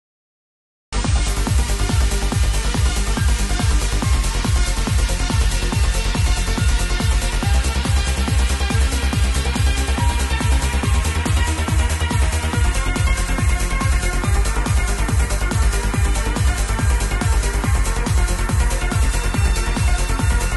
Uplifiting trance ID please